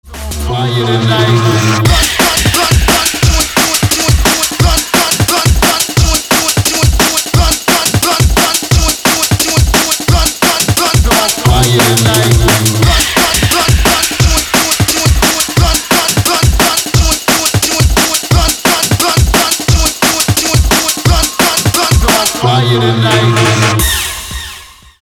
• Качество: 320, Stereo
мужской голос
громкие
dance
Electronic
электронная музыка
drum n bass
Жанр: Jungle, Dubwise, Roots, Bass Music, DnB.